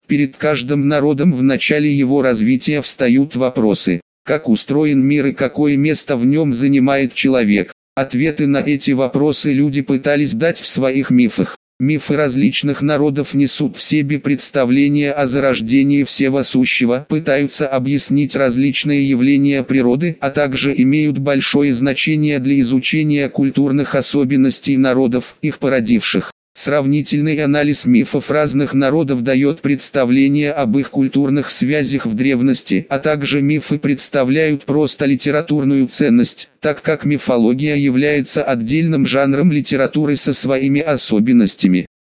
Скачать демонстрационный аудиофайл дополнительного голоса RHVoice [248 kB]
tctts-rhvoice.mp3